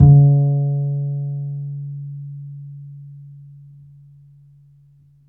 DBL BASS D#3.wav